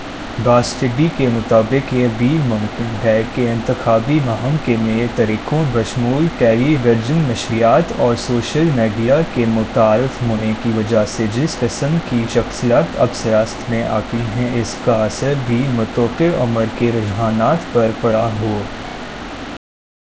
deepfake_detection_dataset_urdu / Spoofed_TTS /Speaker_07 /107.wav